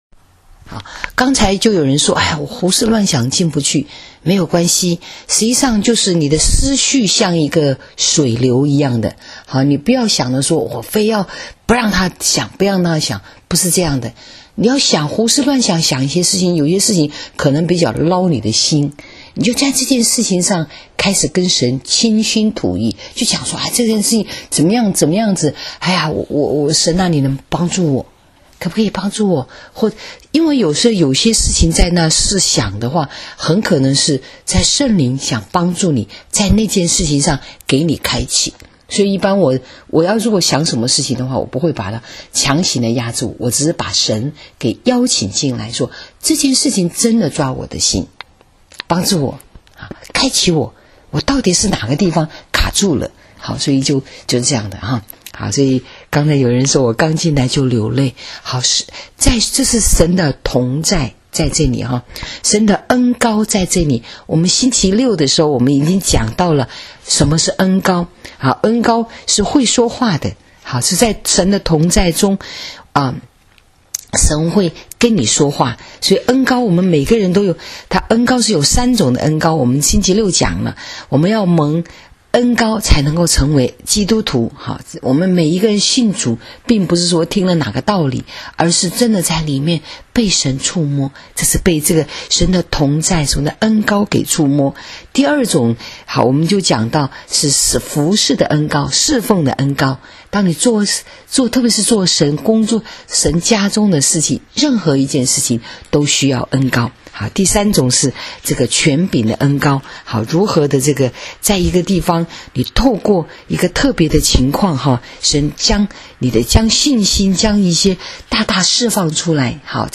【主日信息】恩膏、恩赐与关系 （8-4-19）